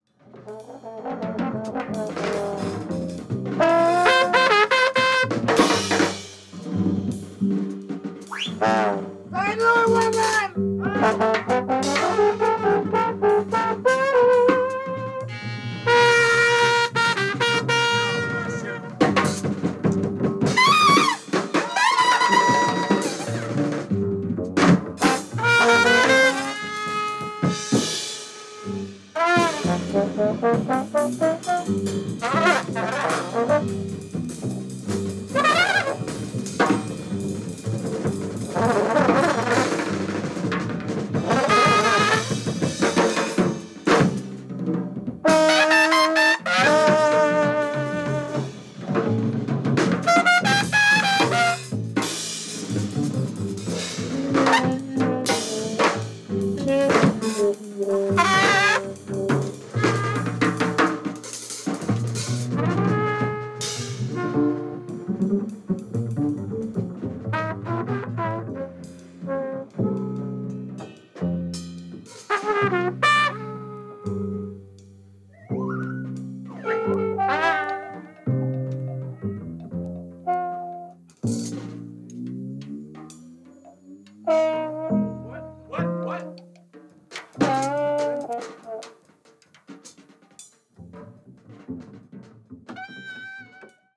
Bass
Congas
Drums
Trombone
Trumpet